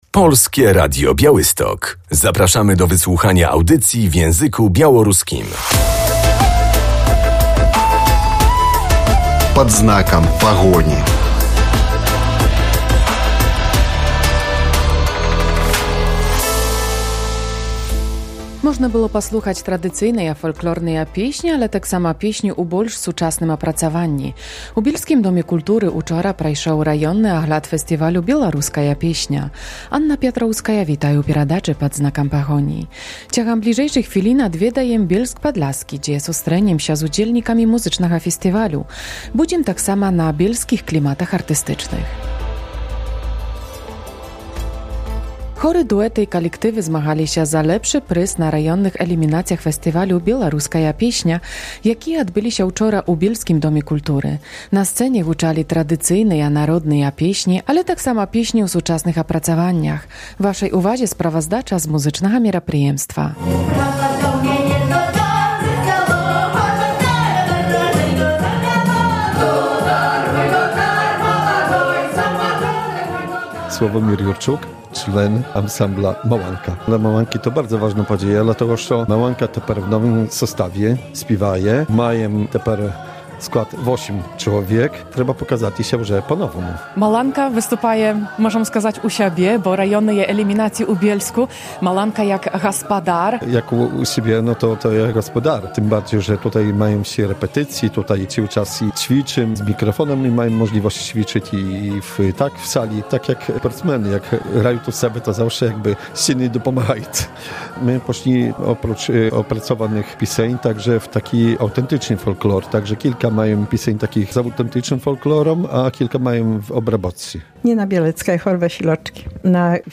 Można było usłyszeć nowoczesne aranżacje, ale też tradycyjne białoruskie melodie. Na scenie Bielskiego Domu Kultury odbyły się eliminacje rejonowe Ogólnopolskiego Festiwalu "Piosenka Białoruska". W audycji spotkamy się z uczestnikami festiwalowych zmagań.